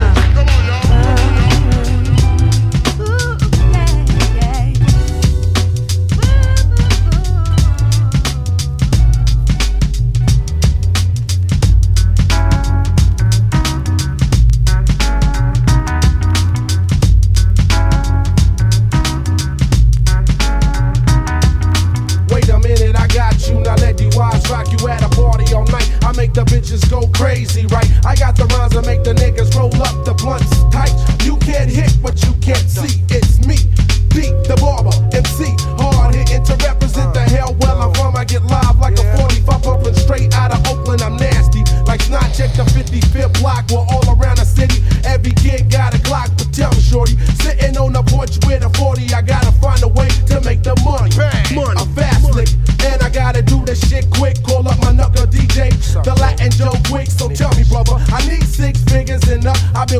Электронная